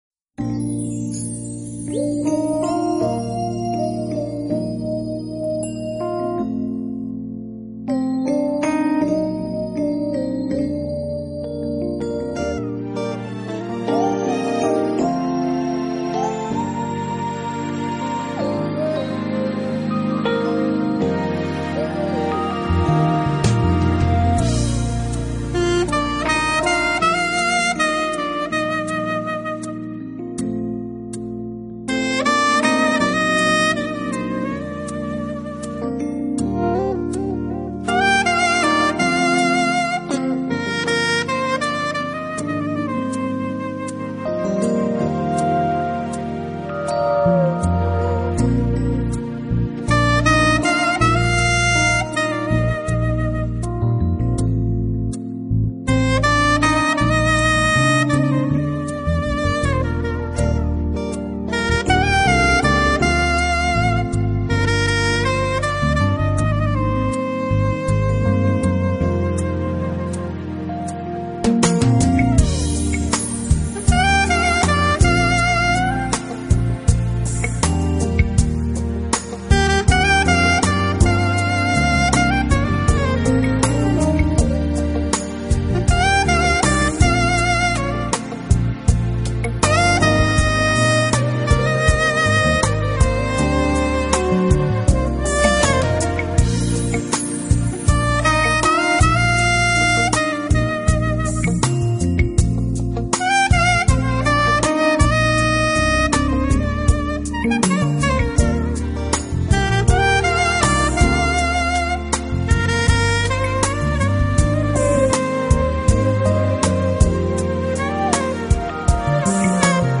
音乐类型：Smooth  Jazz
斯演奏、抒情流畅而悦耳的旋律，还有他那充满深情的蓝调律动给人带来的是震撼。
saxophone
guitar
background  vocals